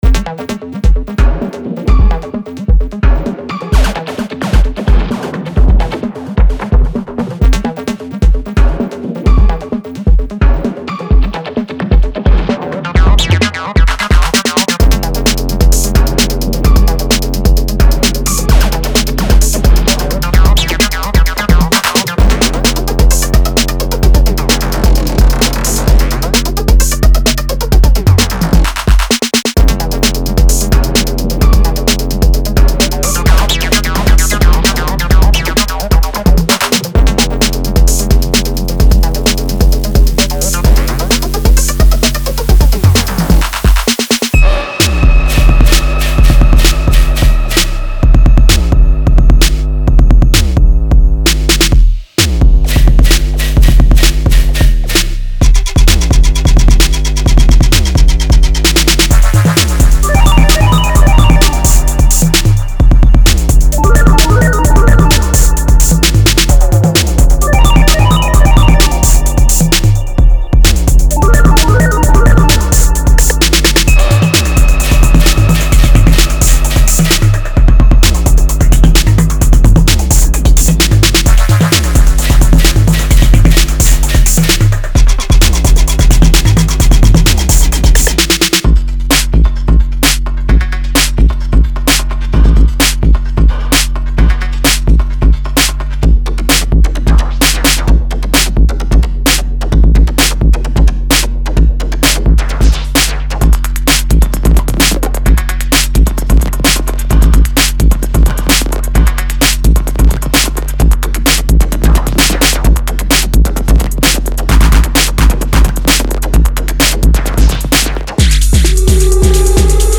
Genre:Electro
デモサウンドはコチラ↓
130 BPM
40 Synth Percussions